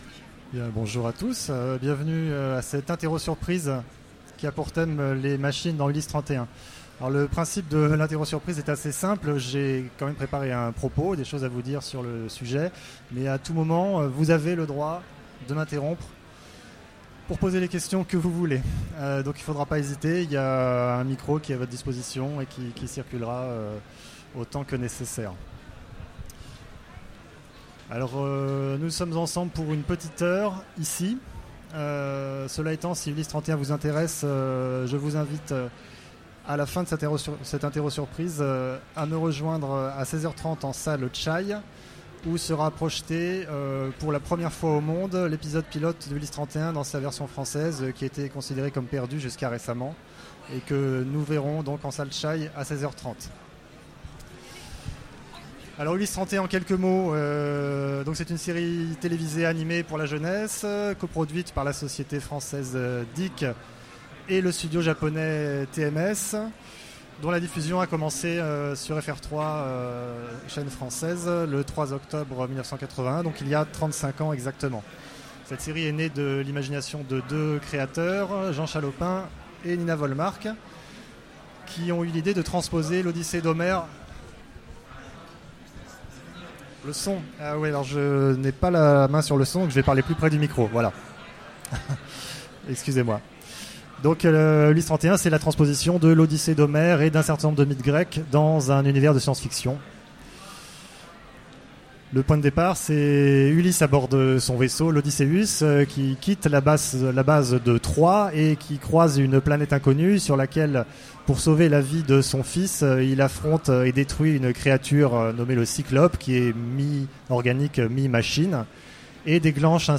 Mots-clés Machine Conférence Partager cet article